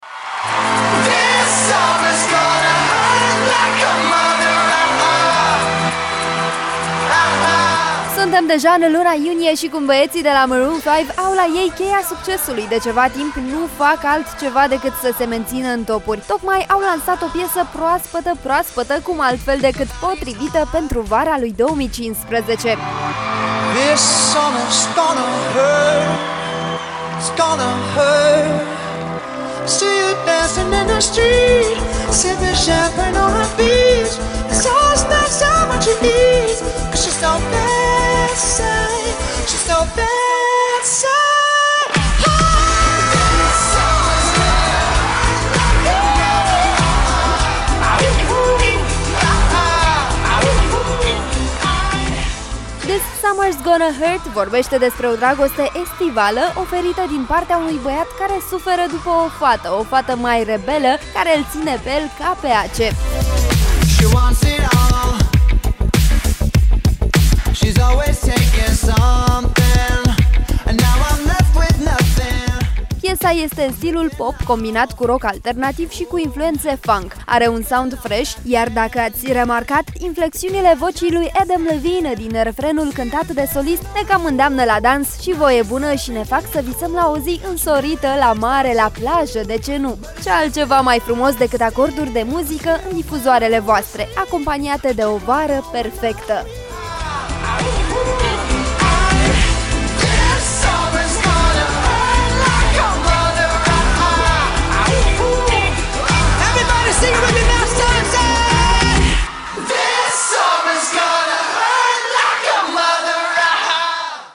Piesa este în stilul pop combinat cu rock alternativ și cu influențe funk.
Are un sound fresh, iar dacă ați remarcat, inflexiunile vocii lui Adam Levine din refrenul cântat de solist, ne  îndeamnă la dans și voie bună și ne fac să visăm la o zi însorită de vară.